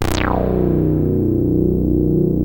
23-SAWRESWET.wav